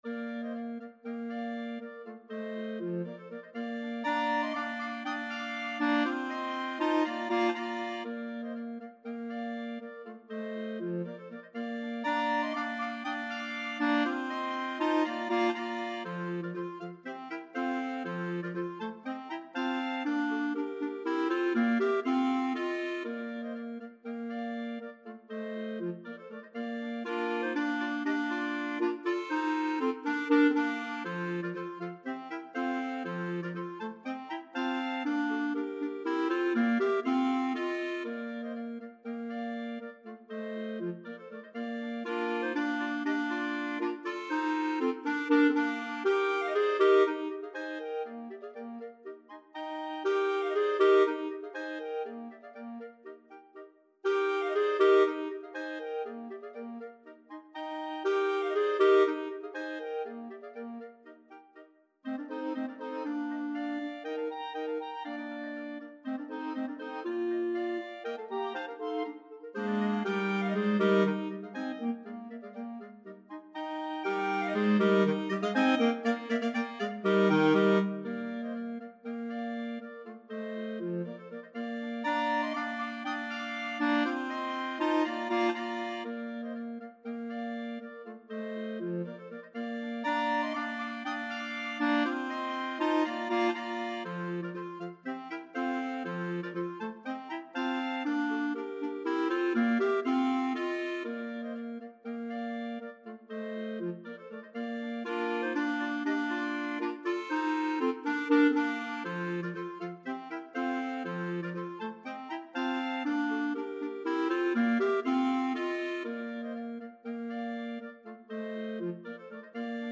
Voicing: Mixed Clarinet Quartet